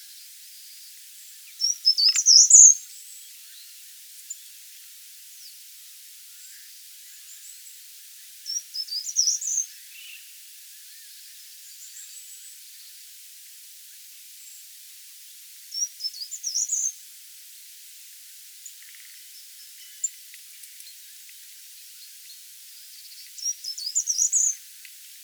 ICI le chant du Grimpereau des jardins, une ritournelle brève, sonore et claire pouvant s'écrire "tit tit tiTuititit"